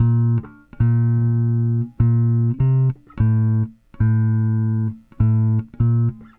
Weathered Bass 03.wav